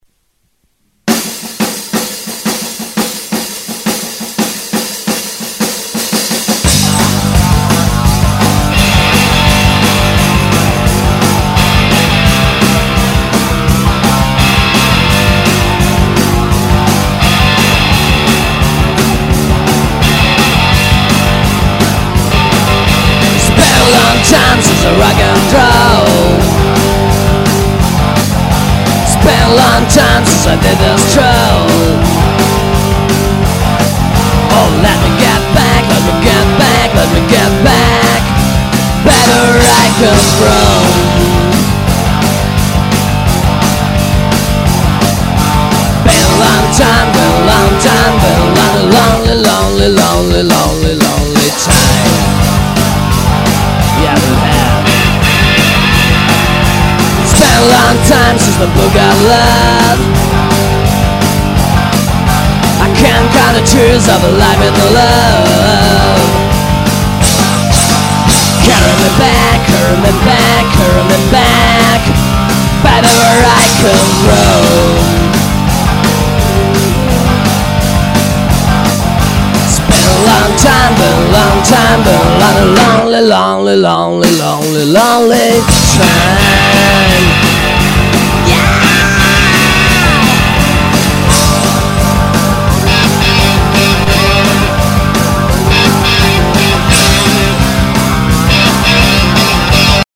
Ñïåòî ýêñïðîìòîì è áåç ðàñïåâêè
Íî ïî÷åìó íà îêòàâó íèæå îðèãèíàëà?
òåìáð ïîíðàâèëñÿ, åñòü ïîìàðêè íî â îáùåì íåïëîõî